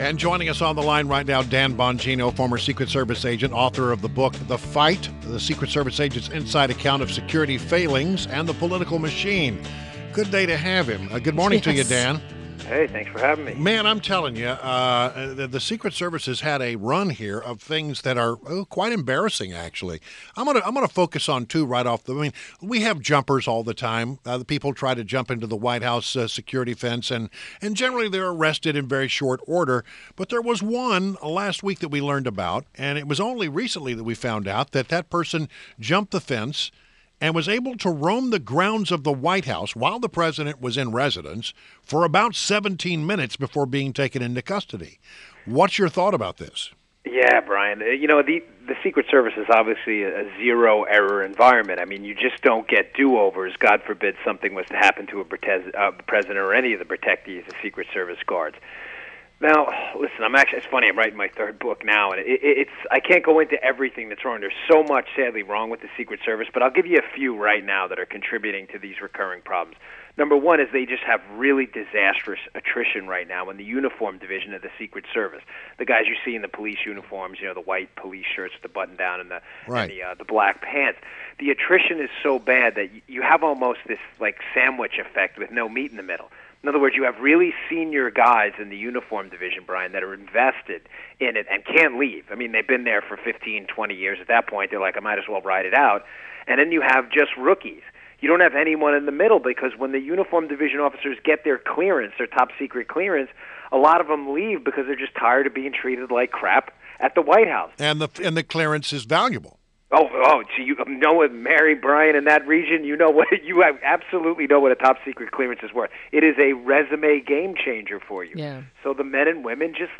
WMAL Interview - DAN BONGINO - 03.20.17